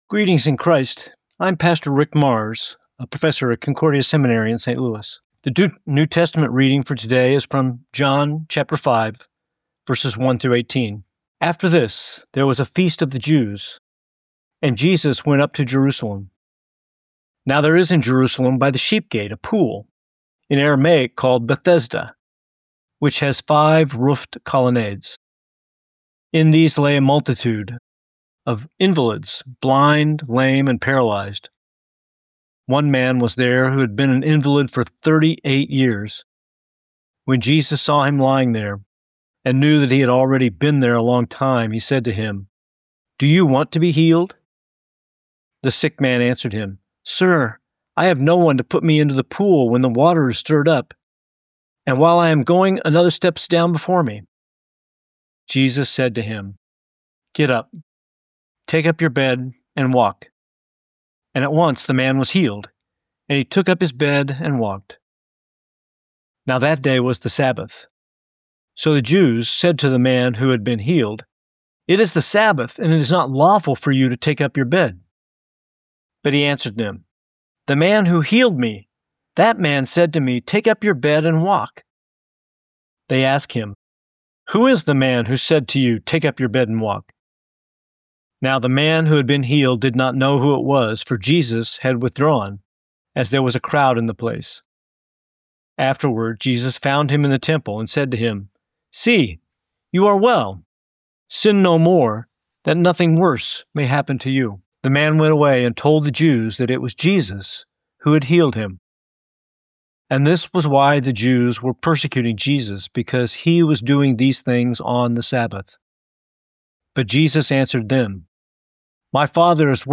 Morning Prayer Sermonette: John 5:1-18
Hear a guest pastor give a short sermonette based on the day’s Daily Lectionary New Testament text during Morning and Evening Prayer.